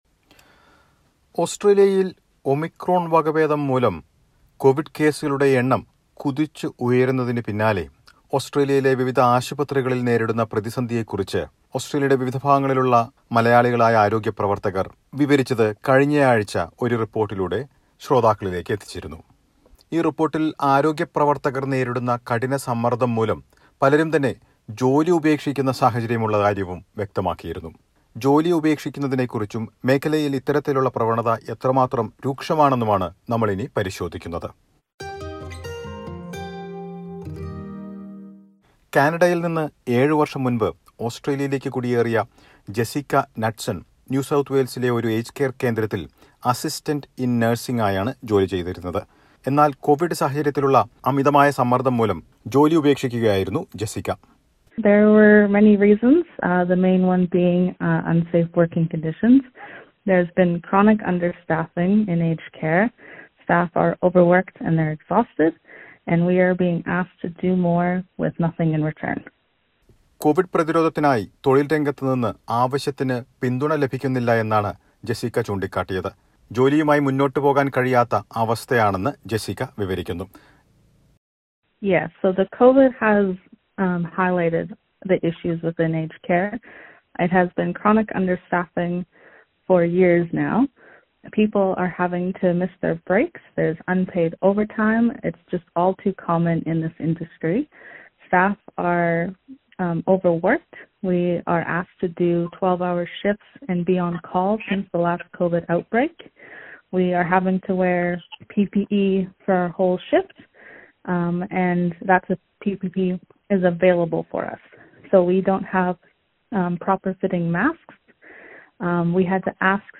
The prolonged battle has left many in the workforce completely 'drained'. The Australian Medical Association and the Nurse’s Federation say many have already left or are planning to leave their jobs. Listen to a report.